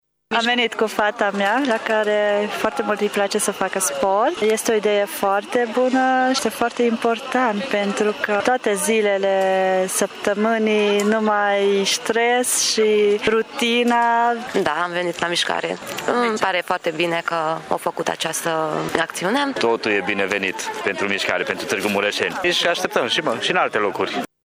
Participanţii spun că le place să facă mişcare şi că astfel de evenimente ar trebui organizate şi în alte locaţii: